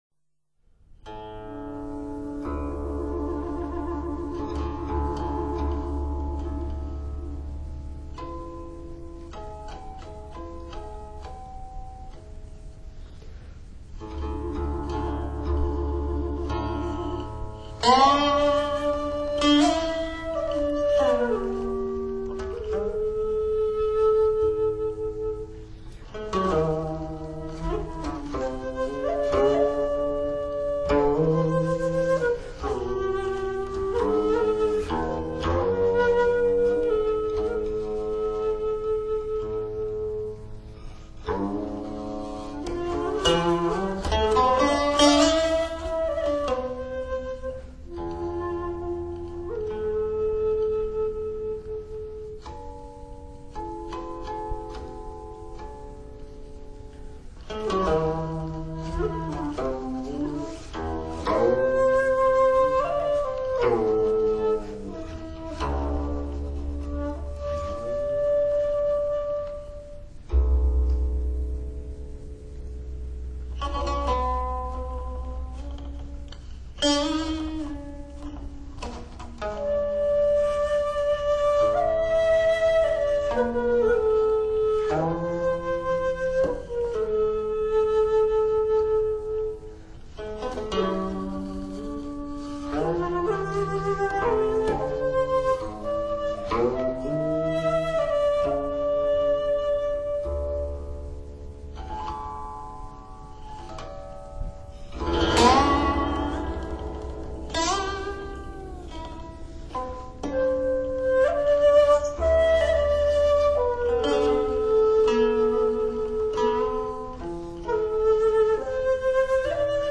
音乐类型:  民乐
竹林中清雅的琴声淙淙而出，一如深潭冥水孤高清寒。